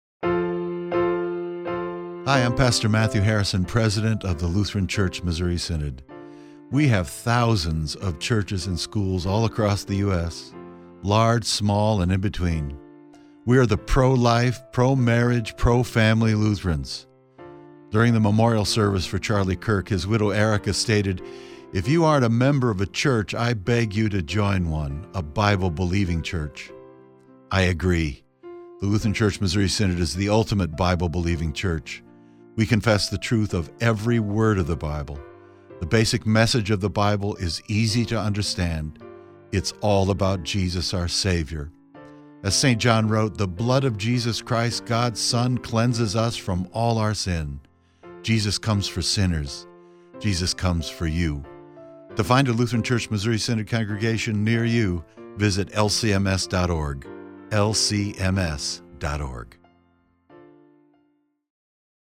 New LCMS Churches radio ad created by Issues, Etc. and voiced by President Matthew Harrison.
lcmschurches.mp3